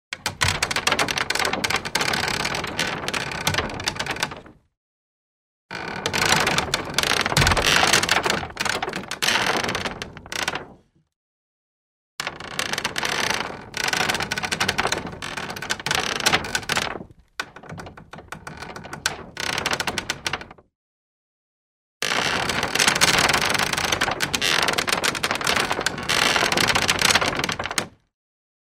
Звуки скрипов
Скрип деревянной двери (медленный)